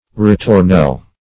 ritornelle - definition of ritornelle - synonyms, pronunciation, spelling from Free Dictionary
Ritornelle \Rit`or*nelle"\, Ritornello \Ri`tor*nel"lo\, n. [It.